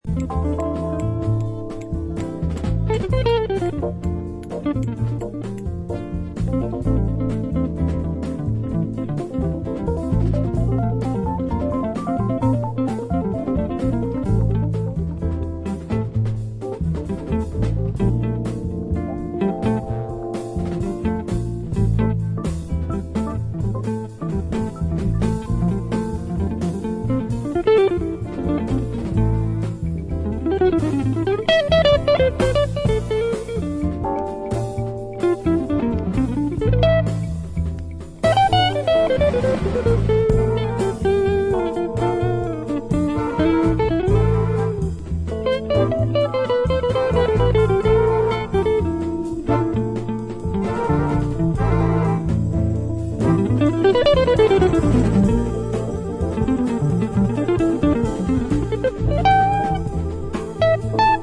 lavish Spanish arrangements
Rhodes
bass
Soul